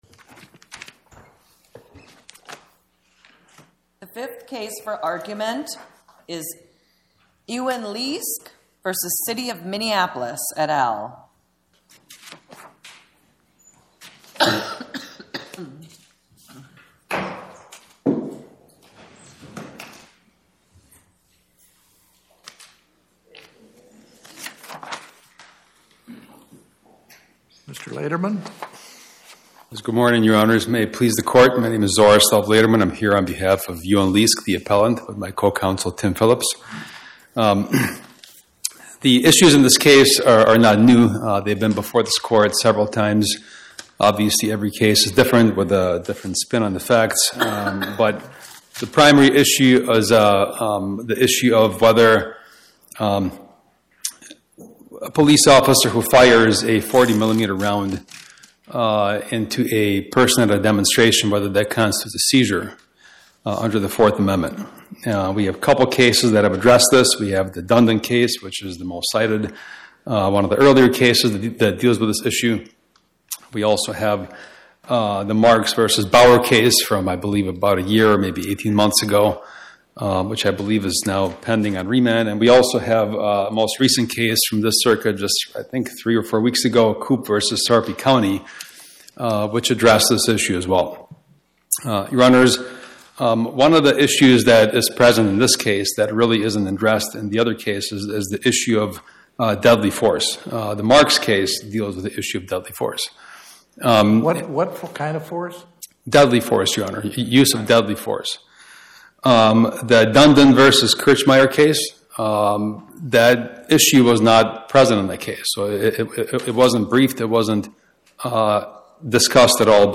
Oral argument argued before the Eighth Circuit U.S. Court of Appeals on or about 12/16/2025